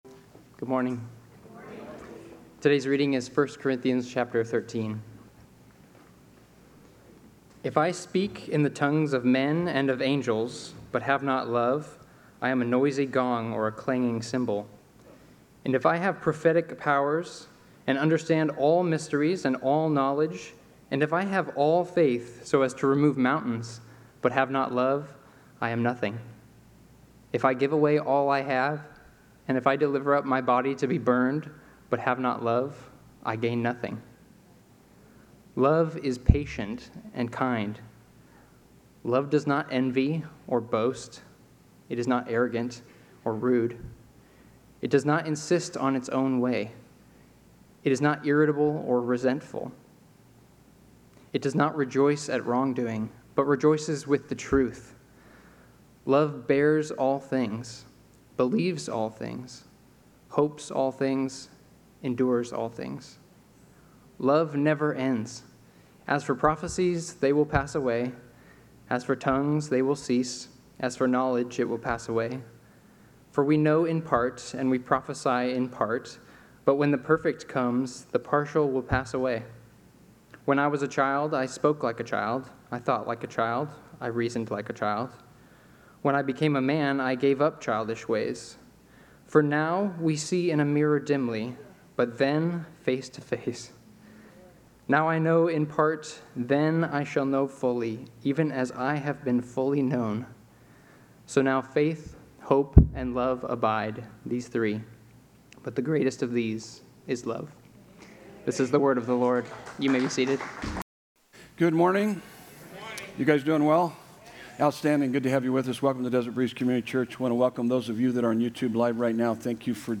Sermon Notes: Love is the Real Flex